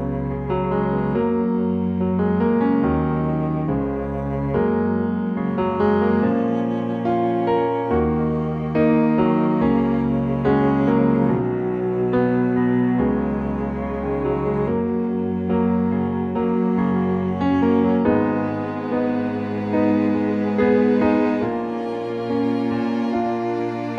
Original Male Key